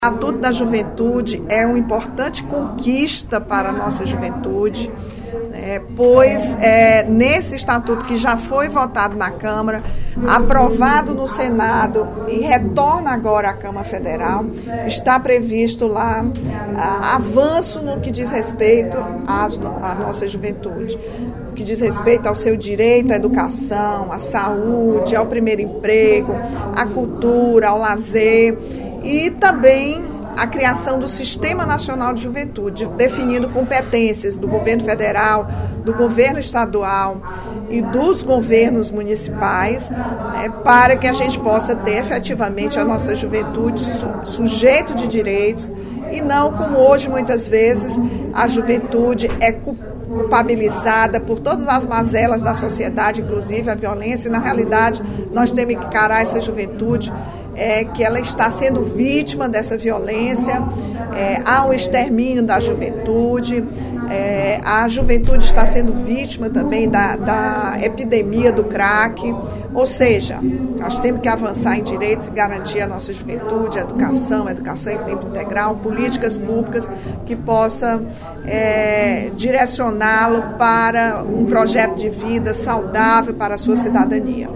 A série de reportagens do jornal Diário do Nordeste sobre a situação dos jovens em Fortaleza foi elogiada pela deputada Rachel Marques (PT), durante a sessão plenária desta quarta-feira (24/04).